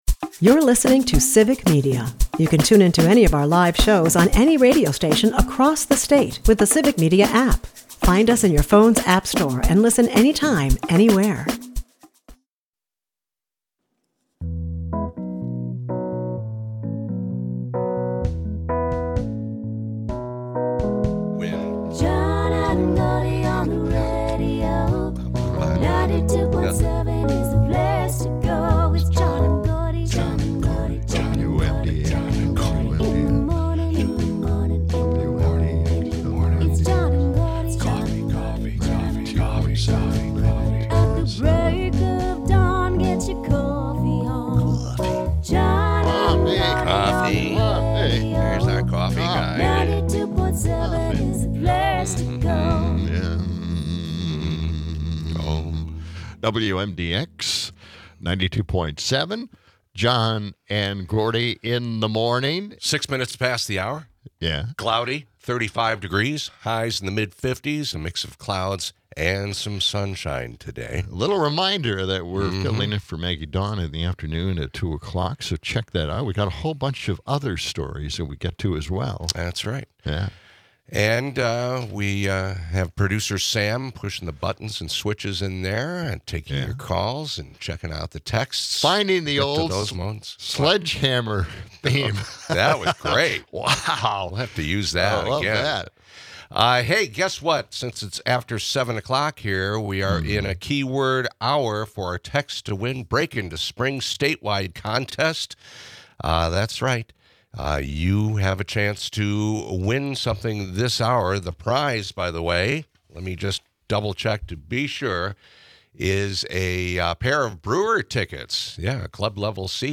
In lieu of a Social Security advocate coming on the show to talk about the importance of protecting the nations most important "entitlements", we turned the mic to the audience and for three straight segments, we took call after call and listened to folks on or affected by social security, we read their texts and livestream comments. All of them express how vital that check is to their lives, and survival as well as what will happen if the current administration takes a machete to the money they've paid into, for so many years.